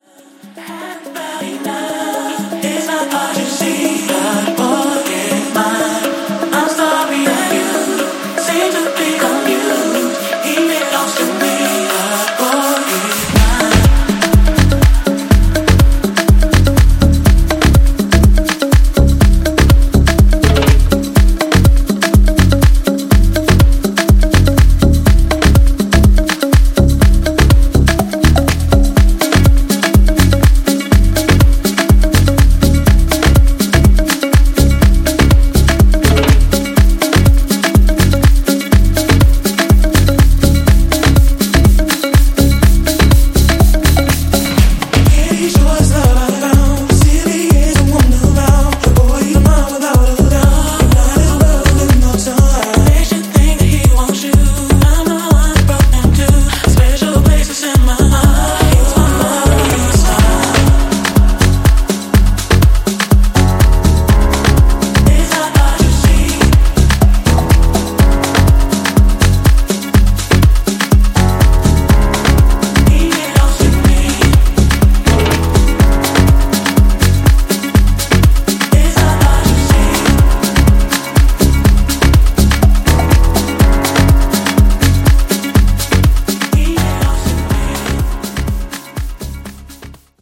以外にもディープでモダンなアフロ・ハウス・スタイルに仕立てており、じっくりハマれる仕上がりとなっています。
ジャンル(スタイル) HOUSE